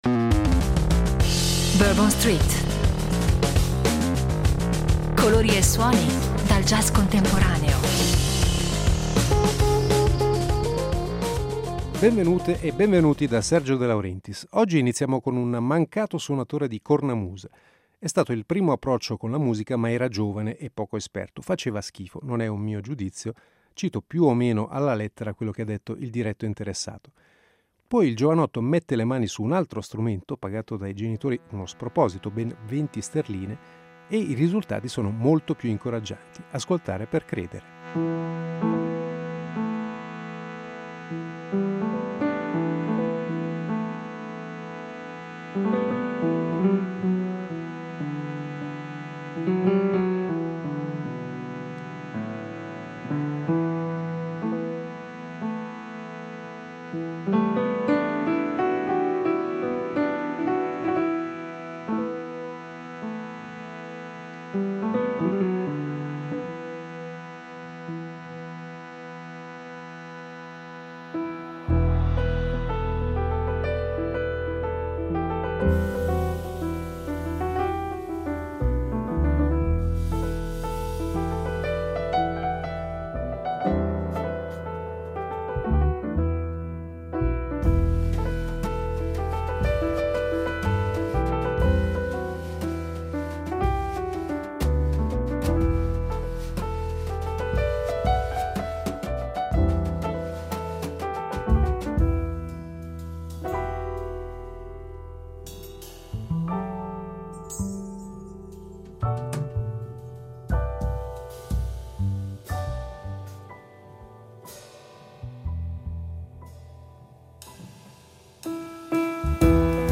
Novità dal mondo del jazz